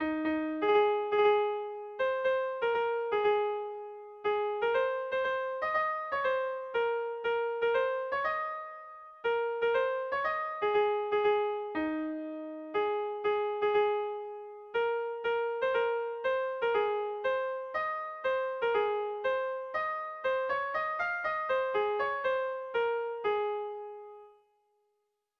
Erromantzea
ABDDE..